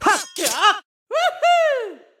Voice clip from Mario Kart 8 Deluxe